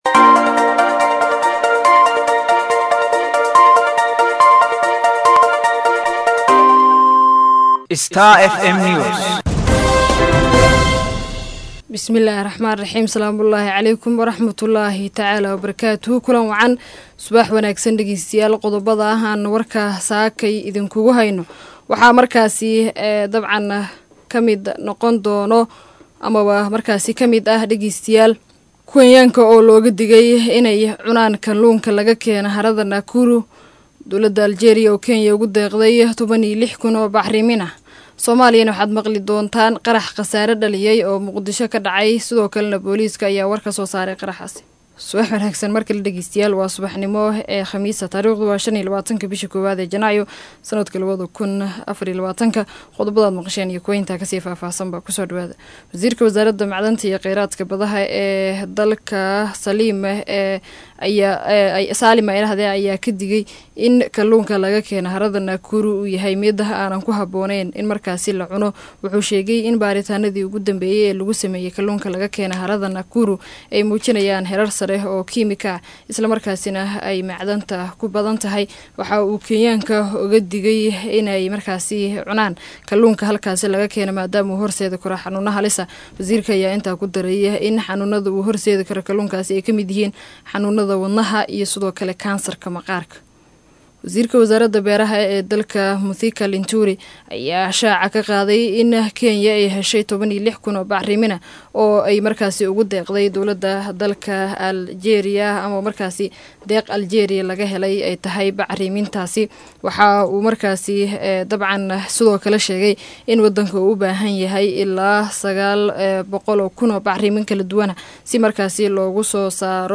DHAGEYSO:WARKA SUBAXNIMO EE IDAACADDA STAR FM